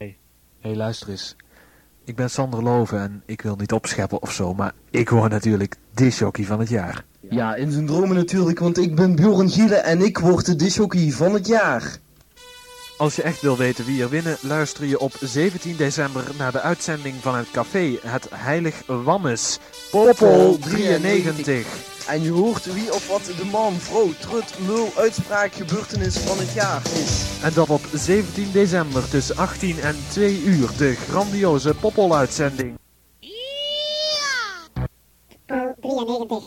download Promotiejingle Pop-Poll-uitzending